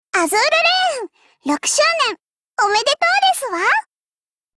全域文件用途 以下其他wiki使用此文件： zh.moegirl.org.cn上的用途 碧蓝航线/六周年登录语音 碧蓝航线:小可畏 转码状态 更新转码状态 格式 比特率 下载 状态 编码时间 Ogg Vorbis 未就绪 于2023年6月15日 (四) 13:59错误